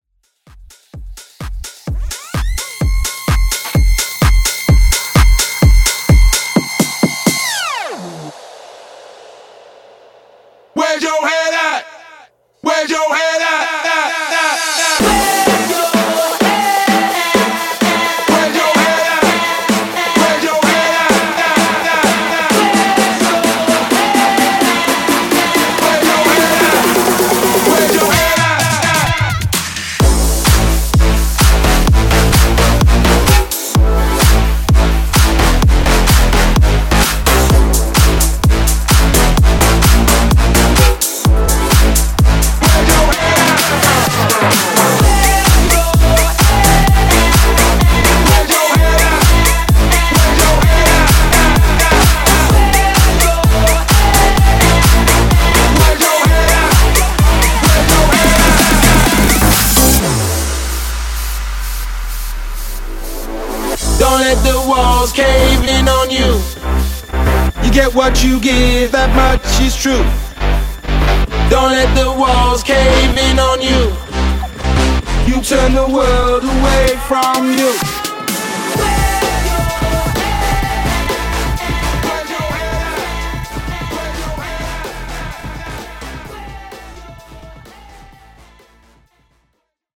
Genres: 80's , R & B , RE-DRUM
Clean BPM: 115 Time